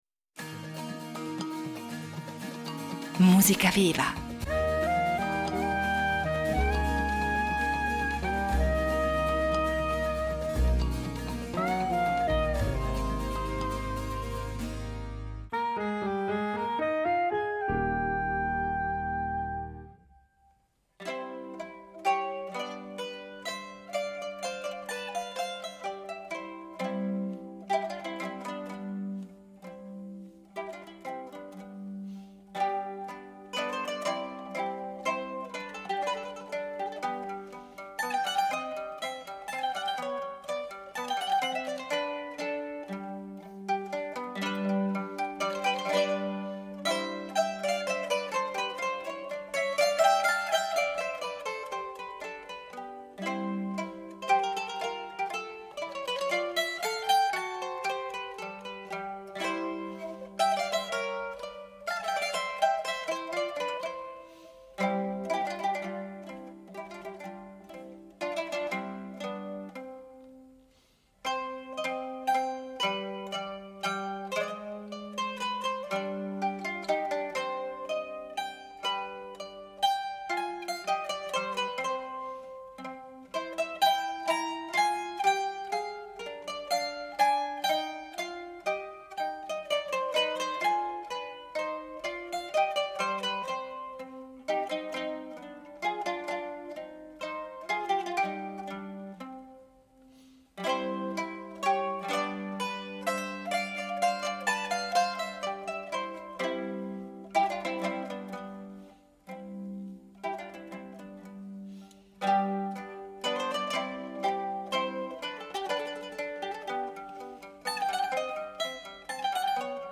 mandolino